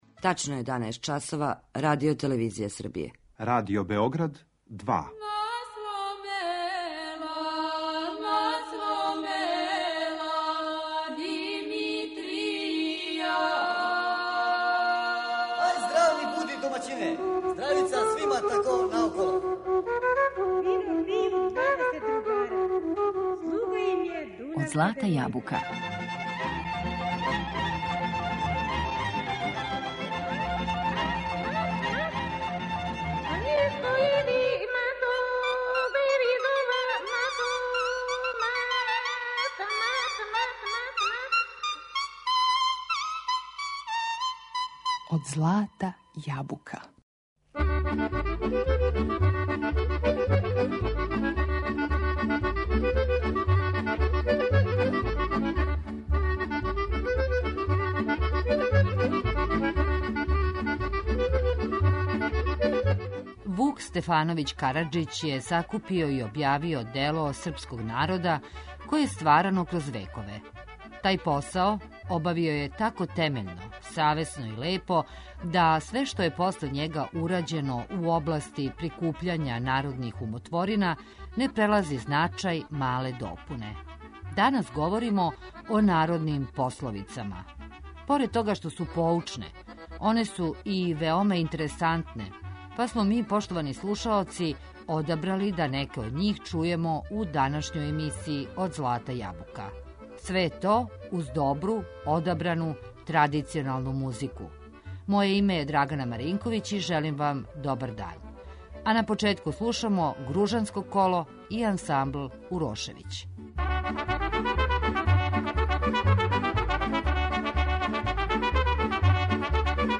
У данашњој емисији Од злата јабука, уз добру традиционалну музику, подсетићемо се неких мудрости из богате ризнице народног блага.